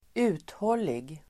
Ladda ner uttalet
Uttal: [²'u:thål:ig]